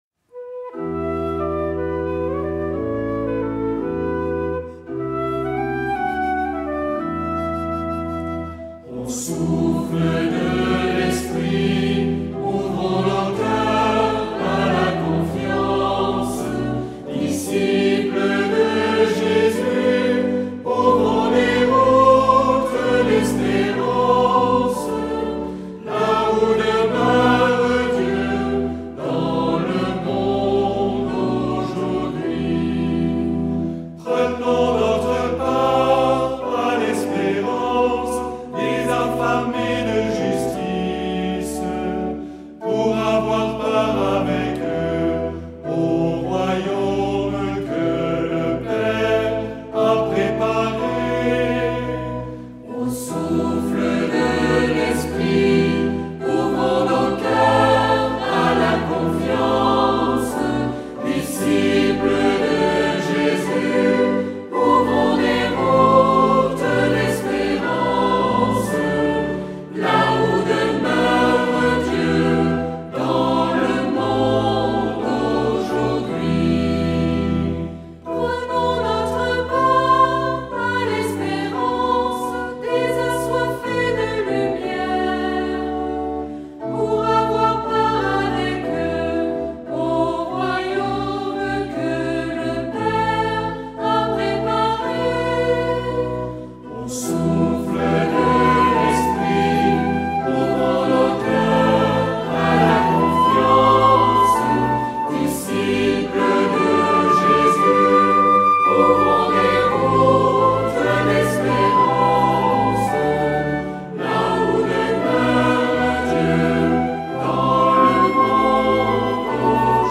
Les morceaux ont été adaptés pour être jouables aisément sur un accordéon diatonique Sol-Do (les diatos joueront la première voix sur la partition) .